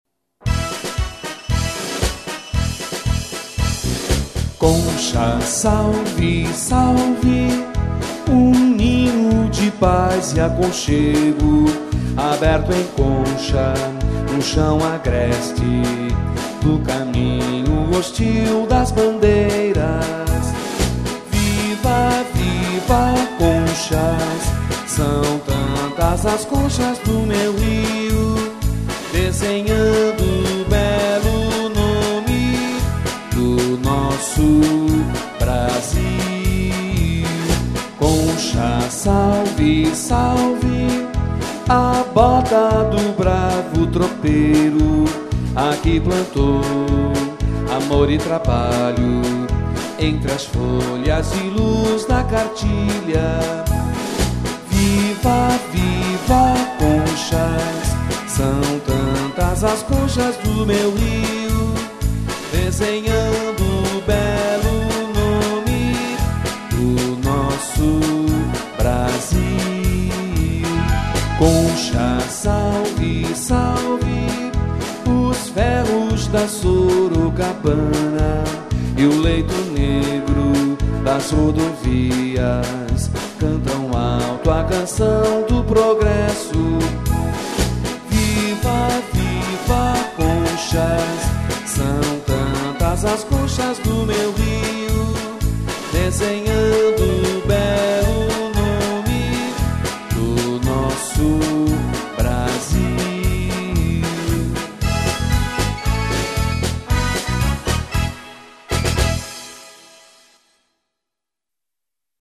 Hino
Letra Luiz José Rodrigues
Música Rossini R. Dutra